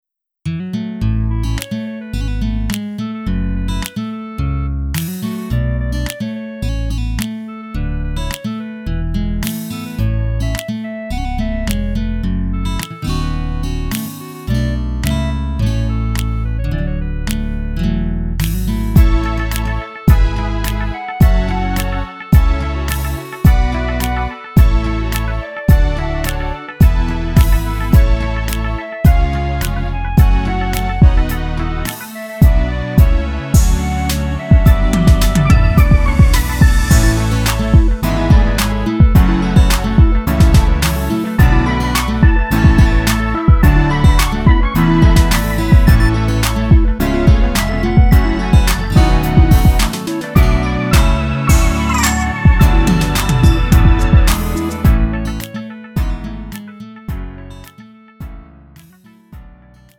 음정 원키 2:53
장르 구분 Lite MR